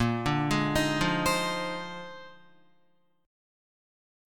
A#11 chord